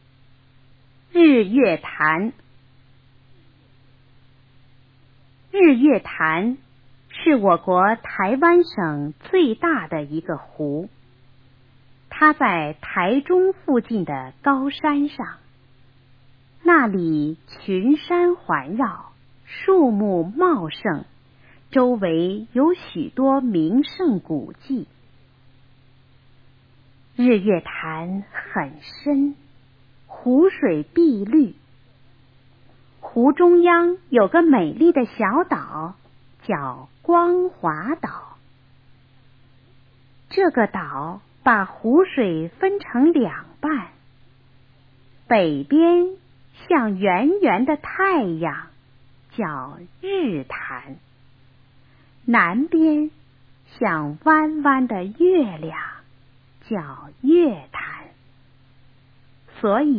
日月潭 课文朗读